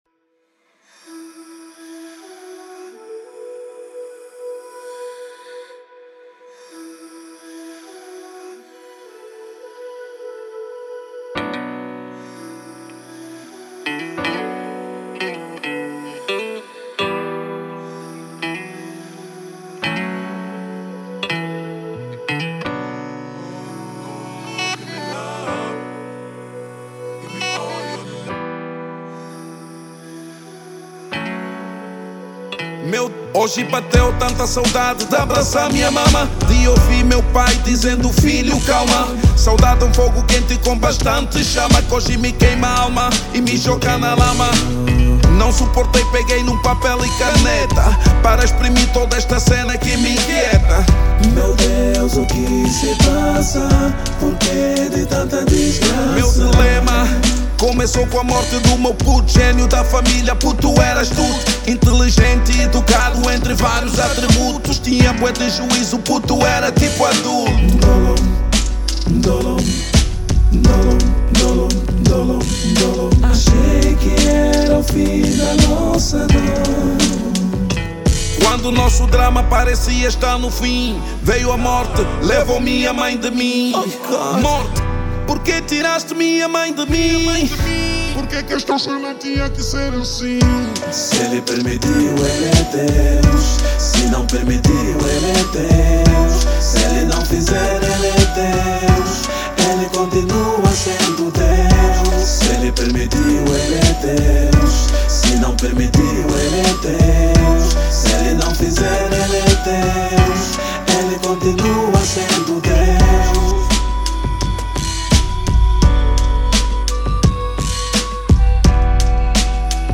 EstiloHip Hop / Rap